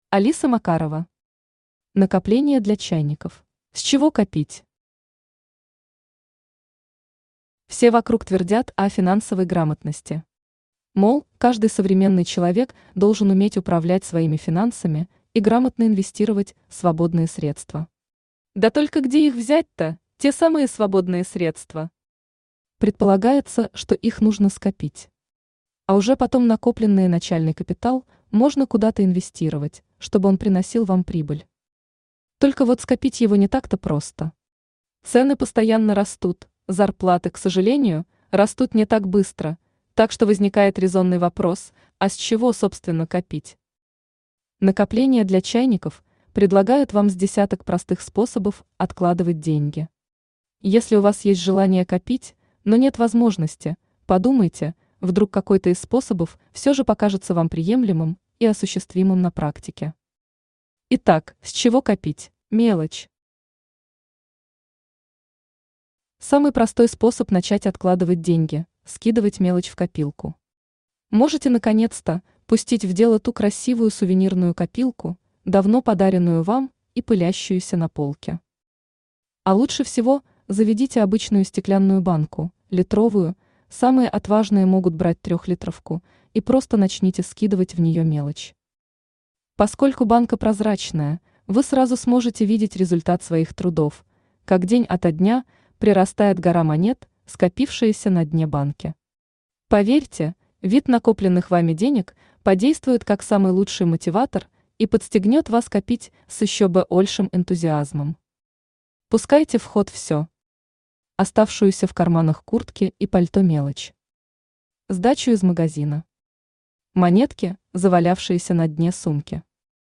Аудиокнига Накопления для чайников | Библиотека аудиокниг
Aудиокнига Накопления для чайников Автор Алиса Макарова Читает аудиокнигу Авточтец ЛитРес.